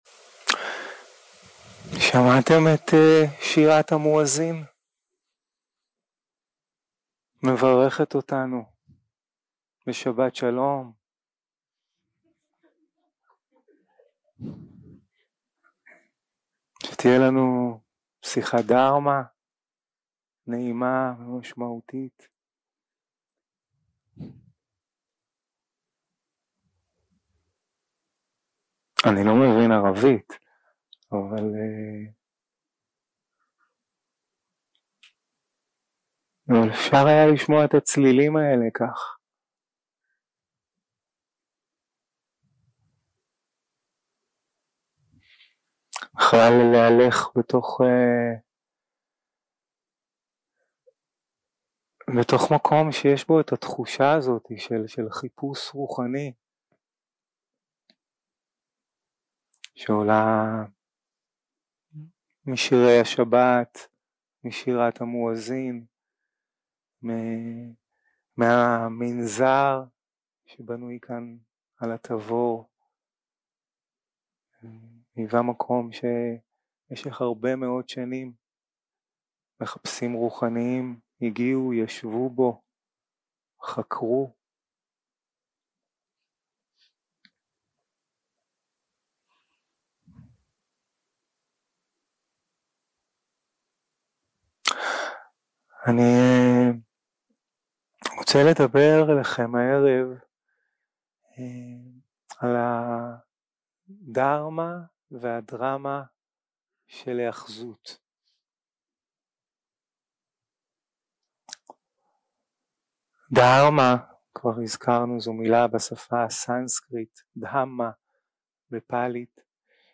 יום 3 – הקלטה 7 –ערב – שיחת דהארמה - הדהארמה והדרמה של היאחזות 0:00 0:00 סוג ההקלטה: Dharma type: Dharma Talks שפת ההקלטה: Dharma talk language: Hebrew